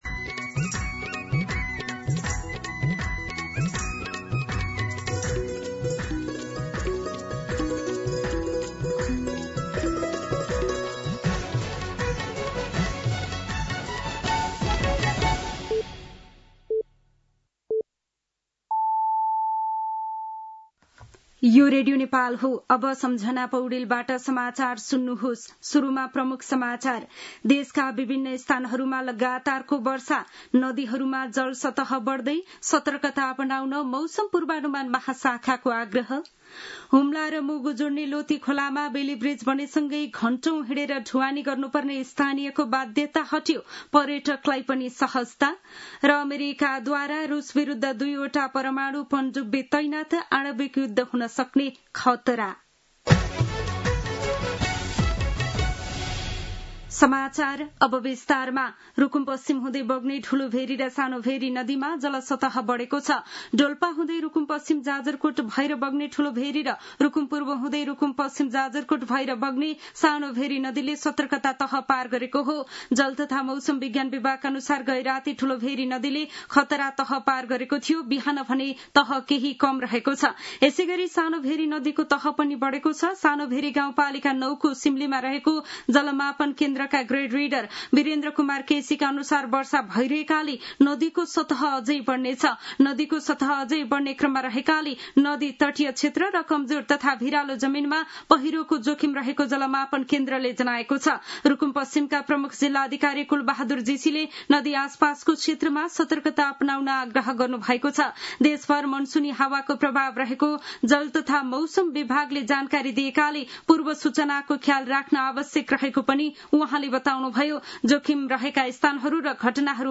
दिउँसो ३ बजेको नेपाली समाचार : १८ साउन , २०८२